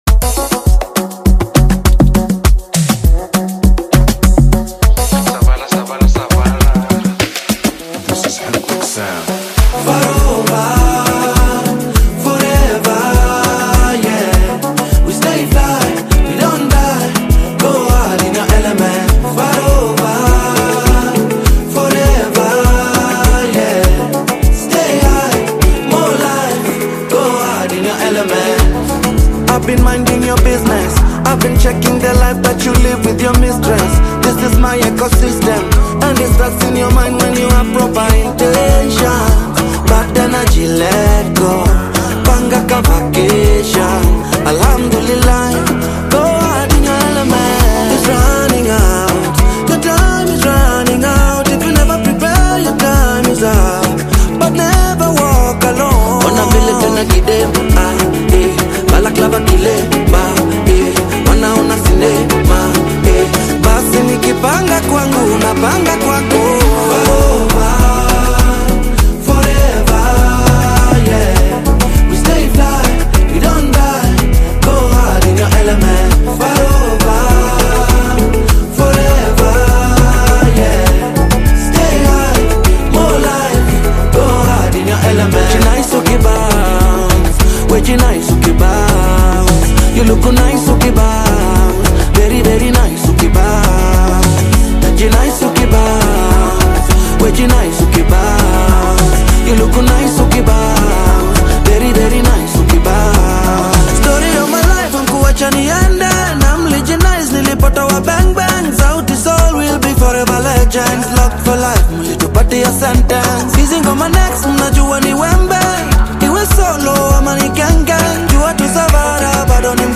he is a force on his own in the African hip-hop scene.